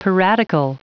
Prononciation du mot piratical en anglais (fichier audio)
Prononciation du mot : piratical